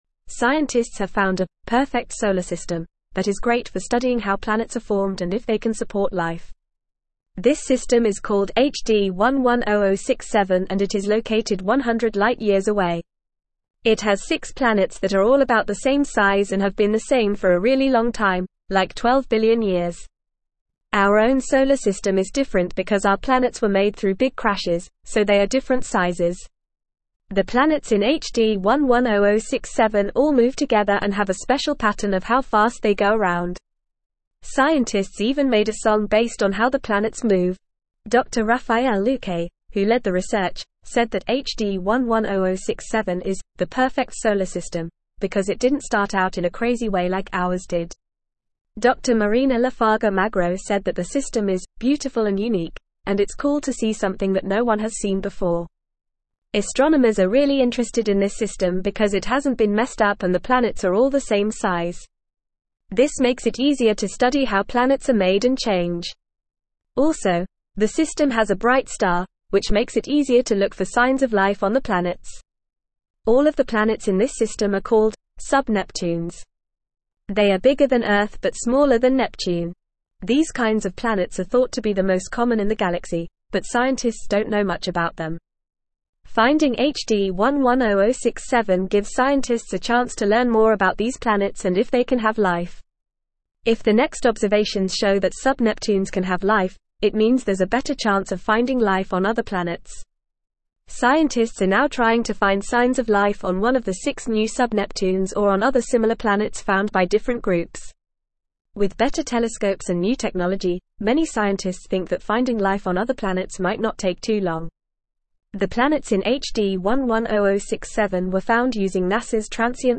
Fast
English-Newsroom-Upper-Intermediate-FAST-Reading-Perfect-Solar-System-Potential-for-Life-and-Discovery.mp3